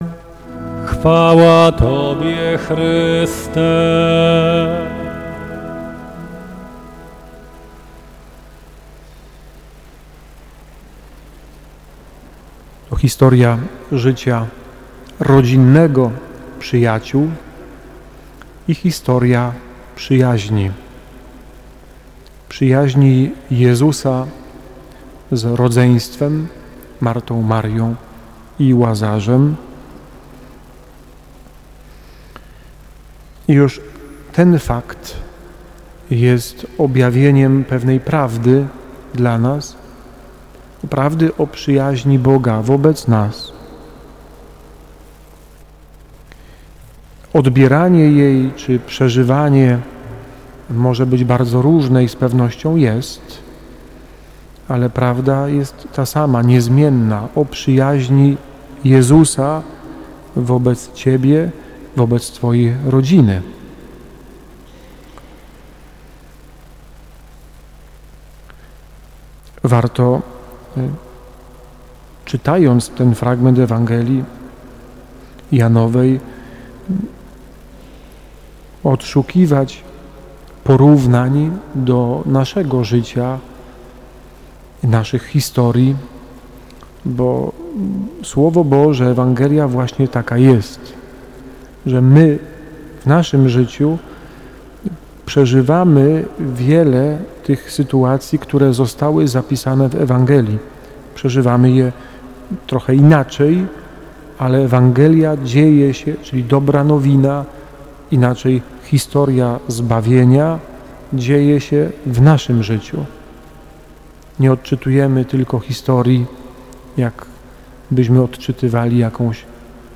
Homilia z mszy świętej 21.03.2026 roku (kliknij w plik)